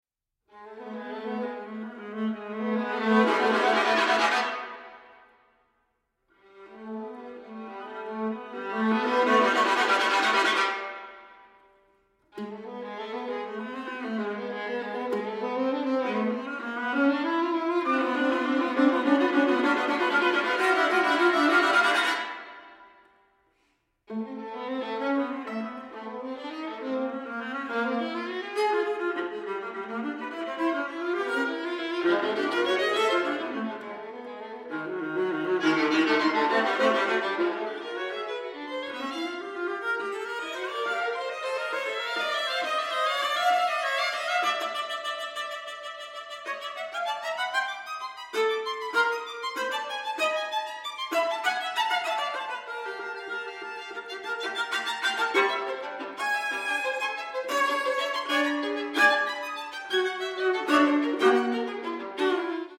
• Genres: Classical, Opera, Chamber Music
Recorded at Evelyn & Mo Ostin Music Center